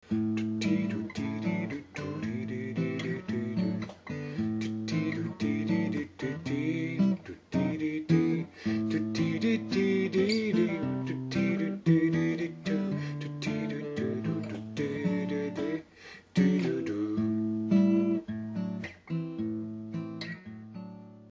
Po celou dobu jsem navíc slyšel dole mezi slovy přeznívat jeden tón. Prázdnou opuštěnou strunu A. Od ní už nebylo daleko ke kytarovému doprovodu a pak